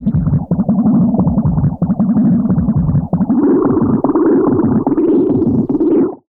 Filtered Feedback 12.wav